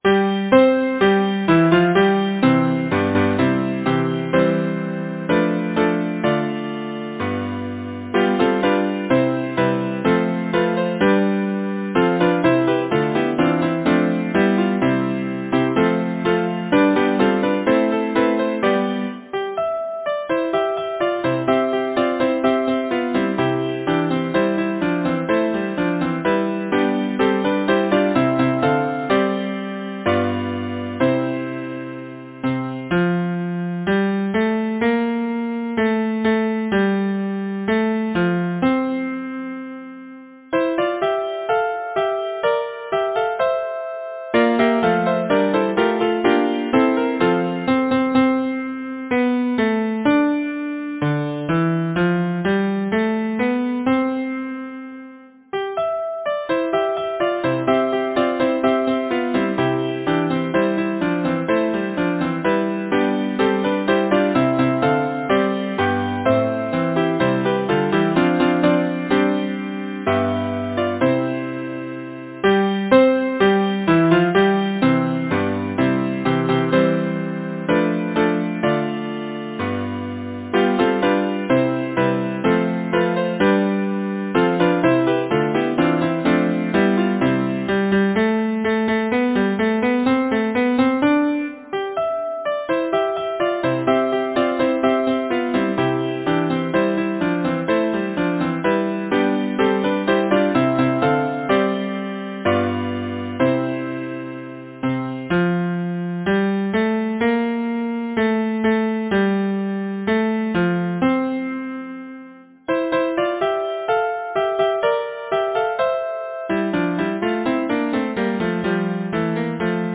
Title: There came three merry men Composer: George Merritt Lyricist: Walter Scott Number of voices: 4vv Voicing: SATB Genre: Secular, Partsong
Language: English Instruments: A cappella